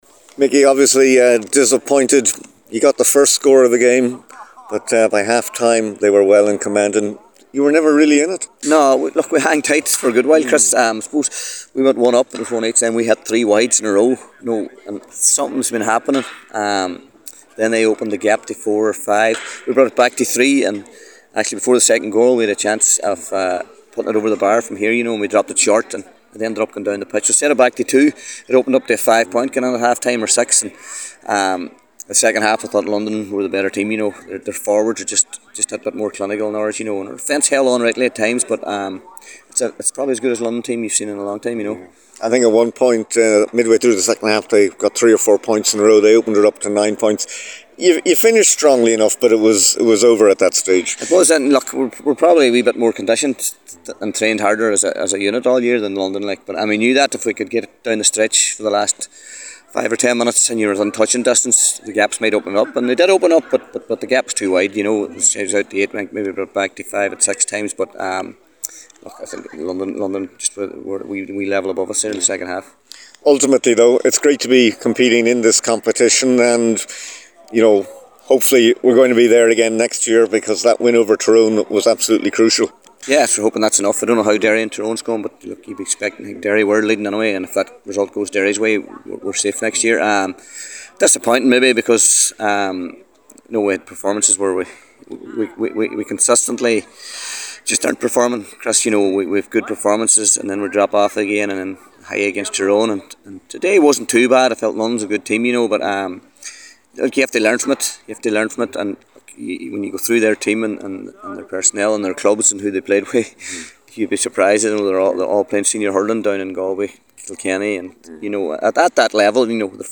gives his post-match thoughts